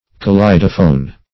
Kaleidophon \Ka*lei"do*phon\, Kaleidophone \Ka*lei"do*phone\,